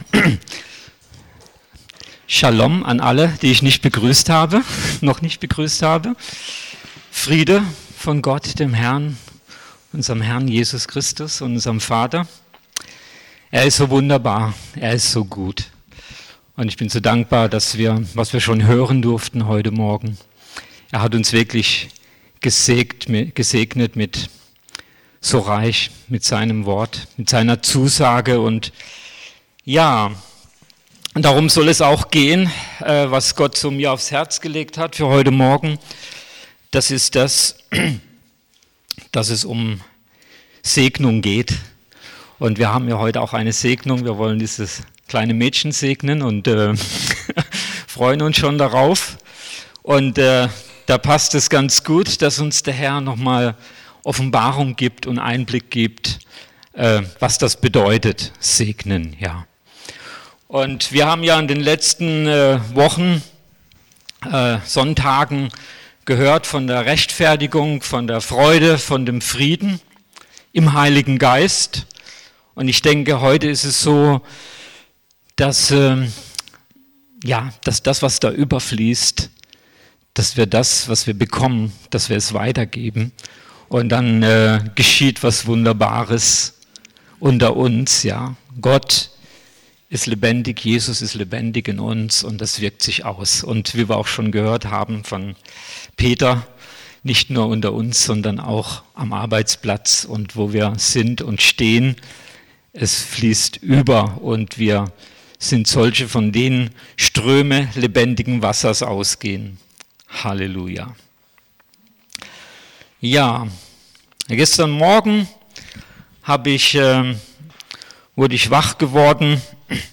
Predigt 14.04.2019